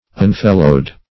Meaning of unfellowed. unfellowed synonyms, pronunciation, spelling and more from Free Dictionary.
Search Result for " unfellowed" : The Collaborative International Dictionary of English v.0.48: Unfellowed \Un*fel"lowed\, a. [Pref. un- + fellowed.] Being without a fellow; unmatched; unmated.